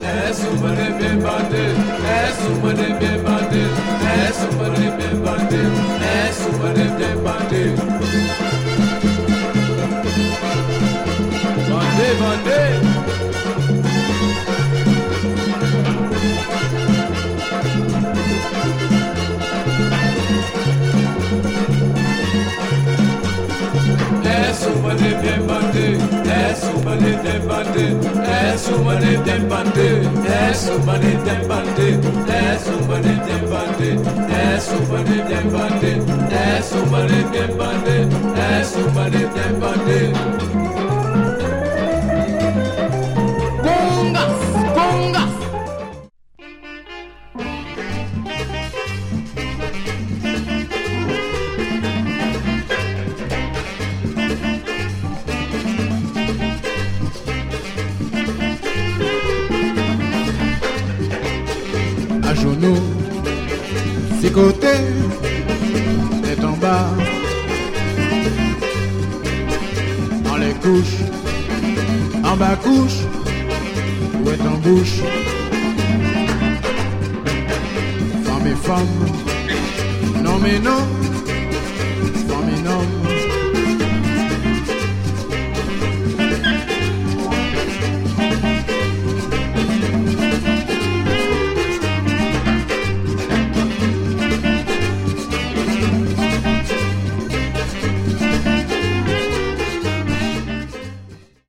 Rare West Indian creole jazz / compas / afro latin album